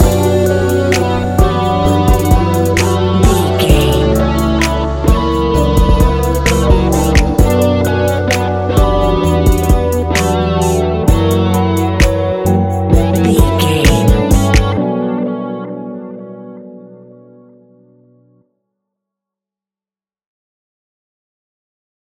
Ionian/Major
B♭
chilled
laid back
Lounge
sparse
new age
chilled electronica
ambient
atmospheric
morphing